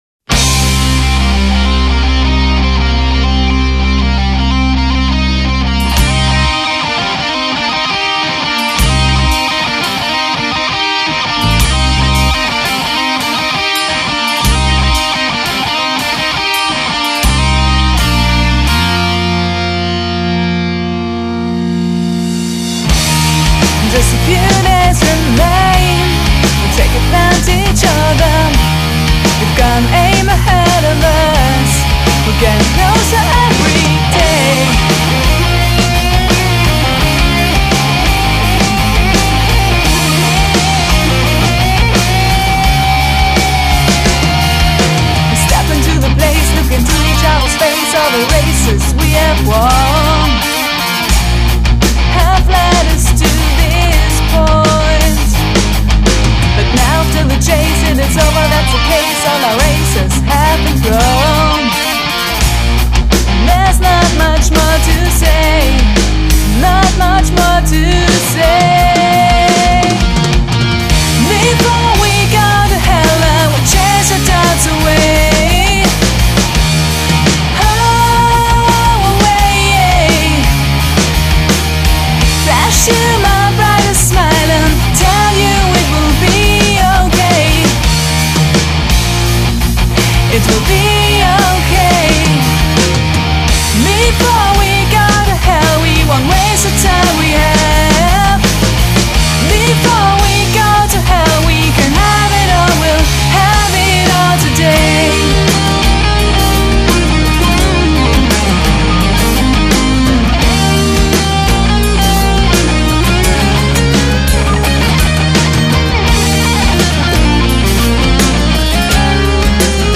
Gesang
Gitarre
Bass, Backgroundgesang
Drums